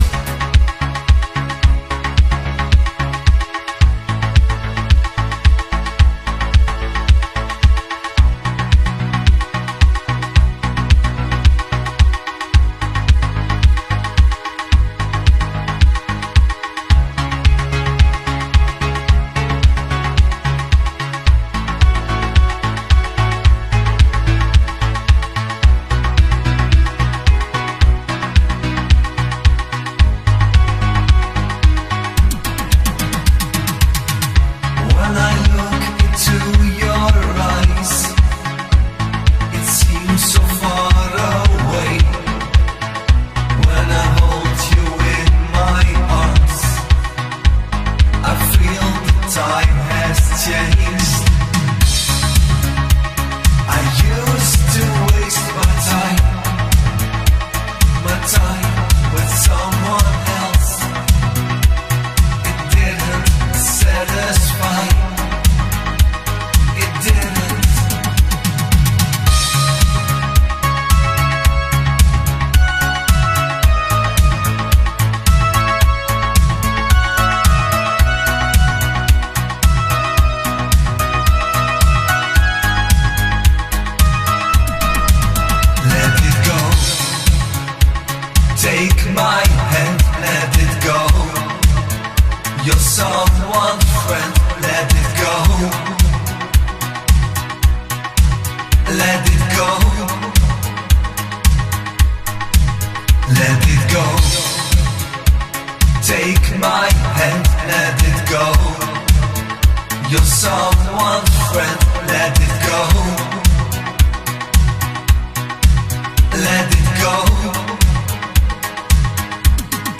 New_Italo_Disco_Mix_3_2020.mp3